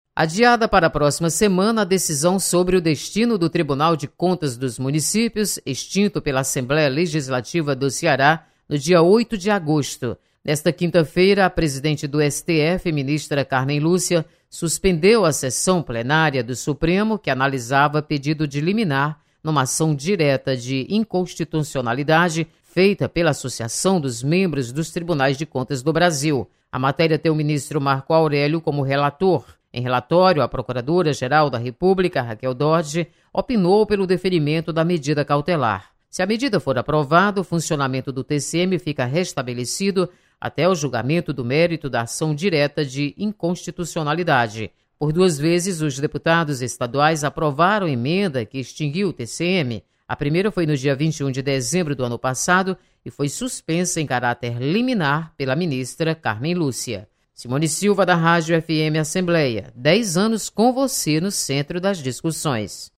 STF adia decisão sobre TCM. Repórter